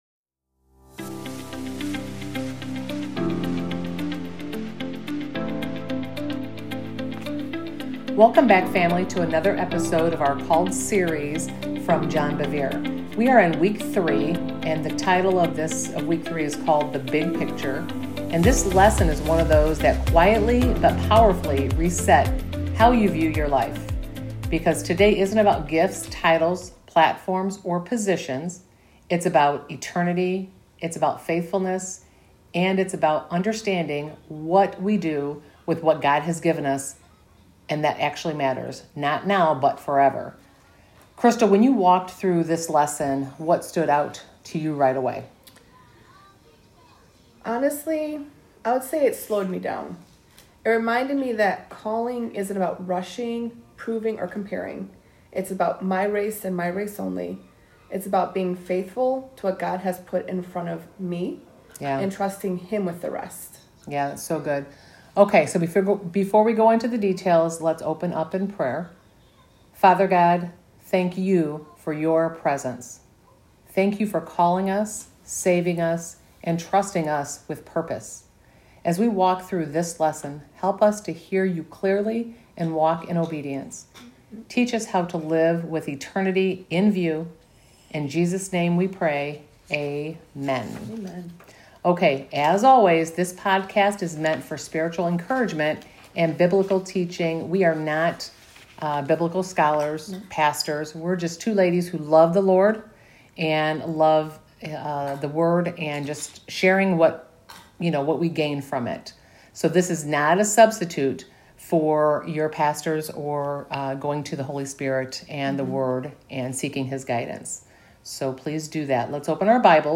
This podcast recaps our Tuesday night Bible Study overview of the CALLED teaching from John Bevere.